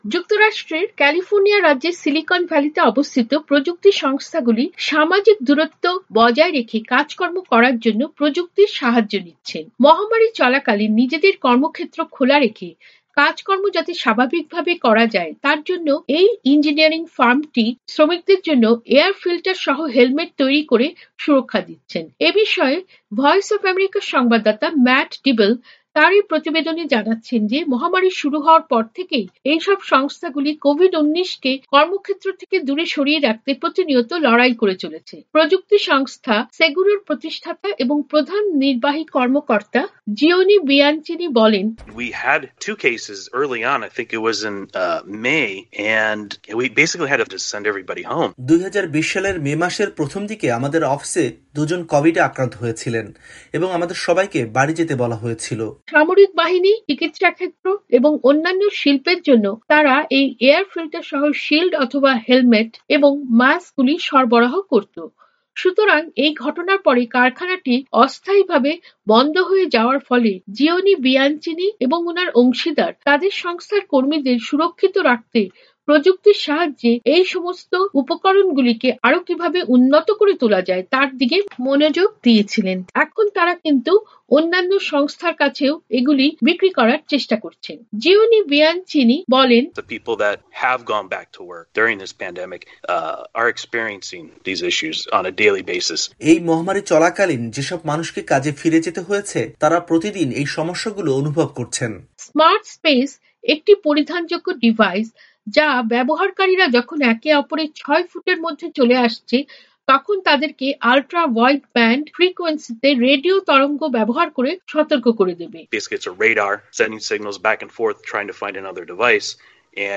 প্রতিবেদনটি পড়ে শোনাচ্ছেন